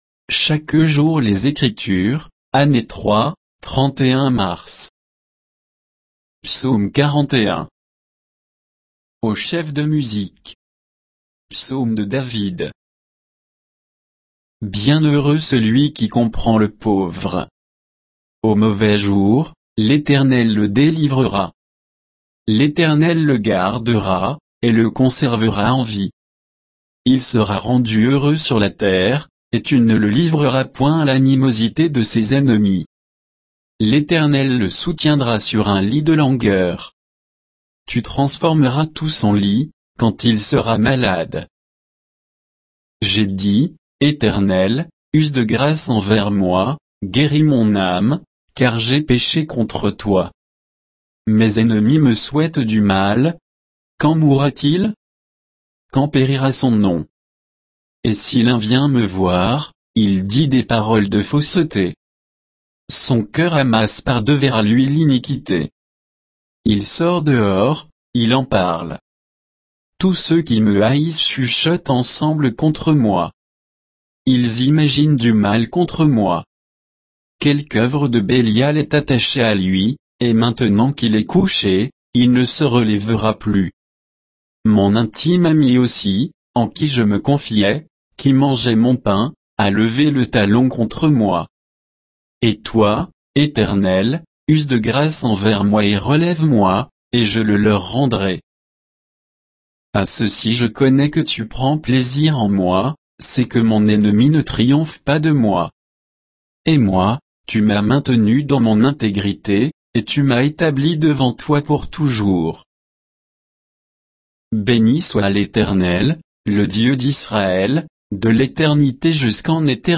Méditation quoditienne de Chaque jour les Écritures sur Psaume 41